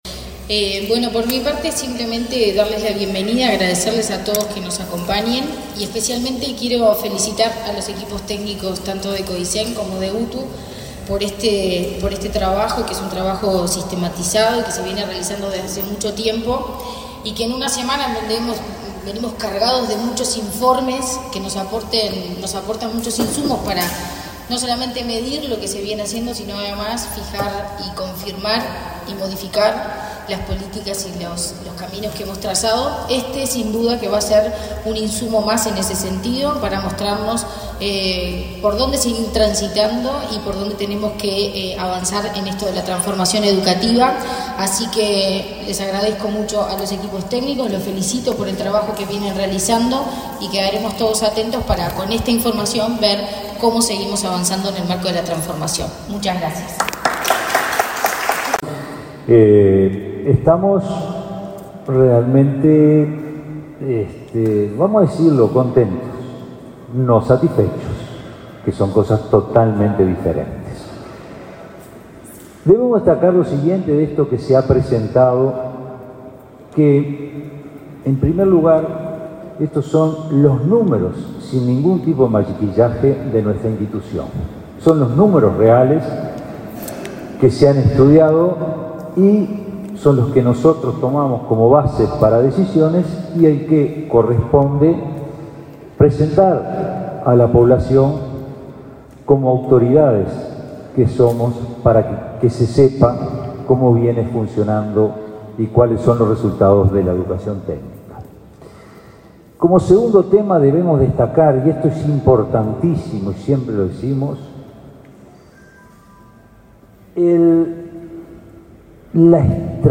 Palabras de autoridades en acto de ANEP